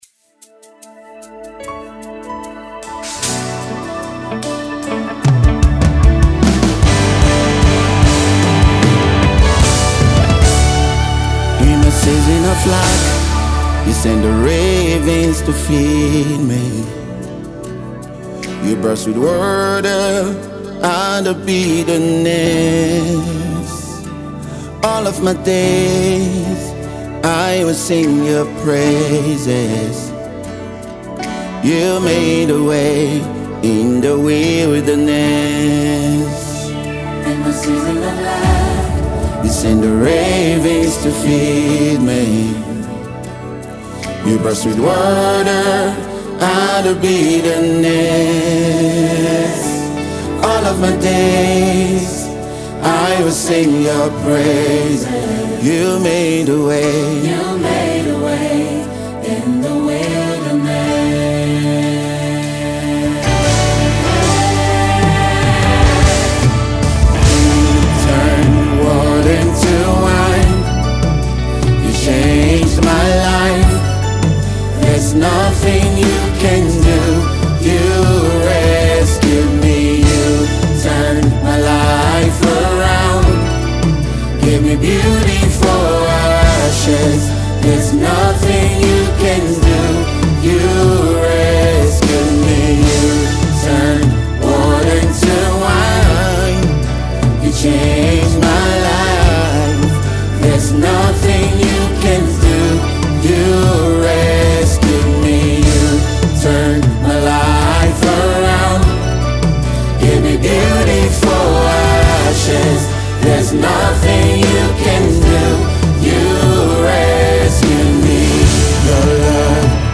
worship leader and singer